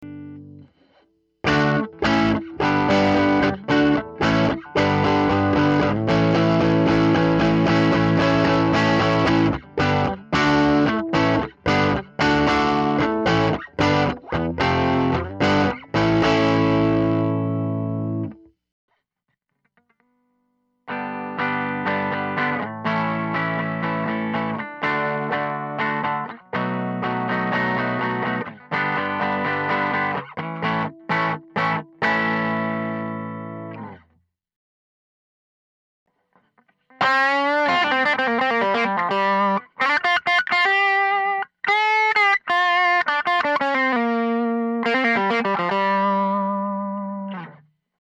High End Buzz on Tube Amp Recording
Recording some electric guitar here and I'm getting a weird high end buzz from my amp. It's hardly noticeable when playing my guitar in practice or at show, but when recording, it is extremely noticeable.
I'm playing the Gibson B.B. King Lucille through a '65 Fender Super Reverb Reissue.
The recording is broken into three parts. The first part has my SM57 pointed straight at the speaker, slightly off center from the cone. The second part has the SM57 about a foot and half from the grille, pointed directly at the center of the amp, in between the 4 speakers. The last part has the same mic configuration but I have my Luther Drive Pedal turned on.